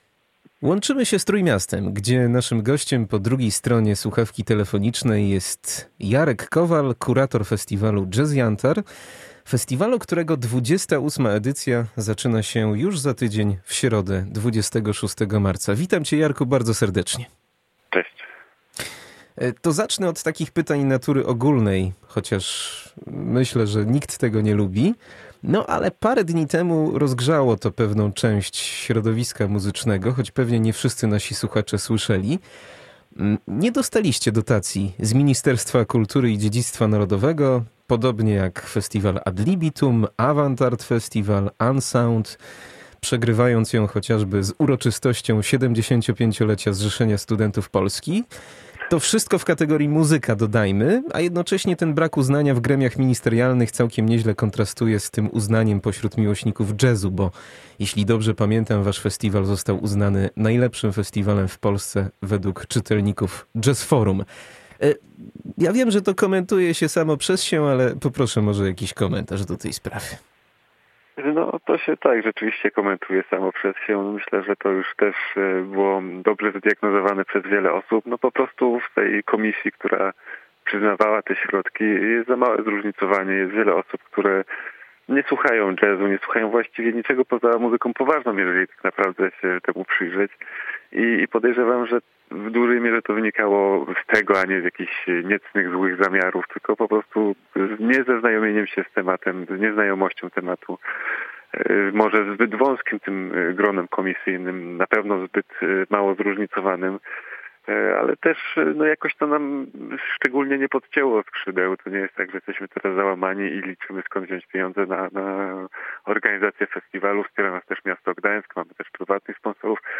W tym roku odbędzie się w odświeżonej formule i z naprawdę mocarnym, zróżnicowanym programem! Porozmawialiśmy o nim szczegółowo z jego pomysłodawcą.